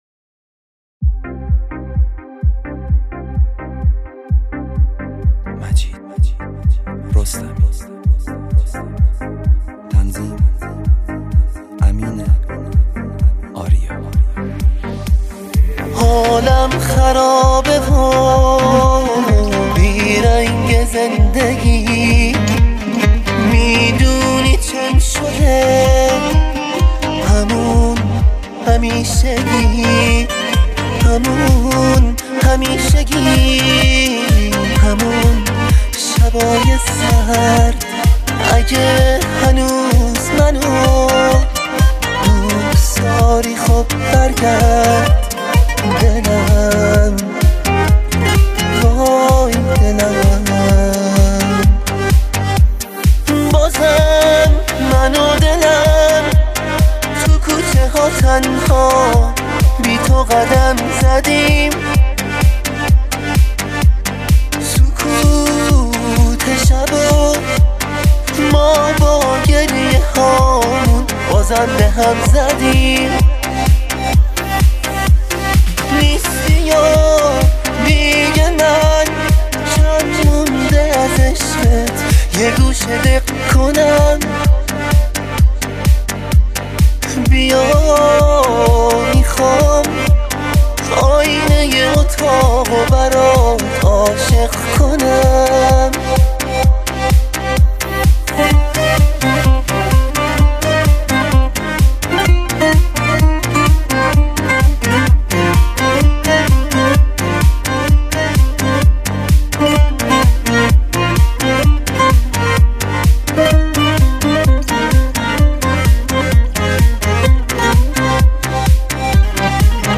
بوزوکی